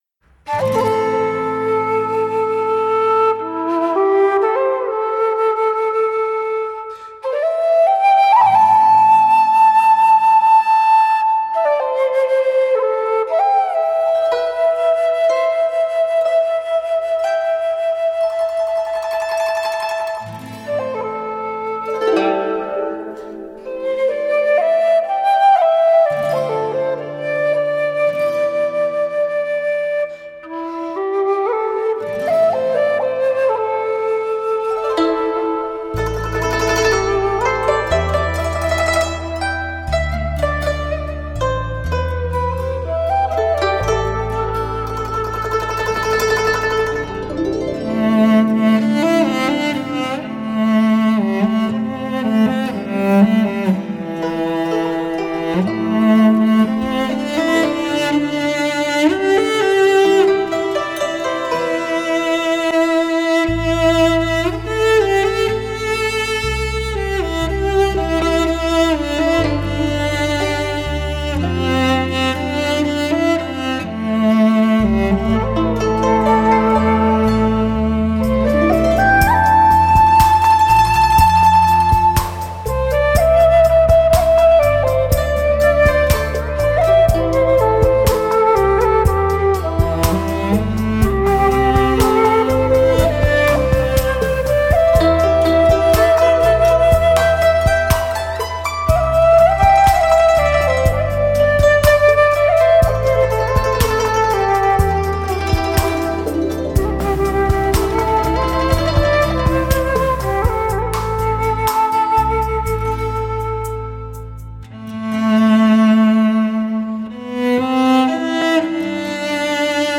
“交响乐”般错落有致的编配
华丽的多声部演奏 精彩“炫技”的器乐表现 特别加强的低频力度
让民族乐器如交响乐一样层次分明
洞箫、古筝、大提琴